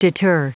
Prononciation du mot deter en anglais (fichier audio)
Prononciation du mot : deter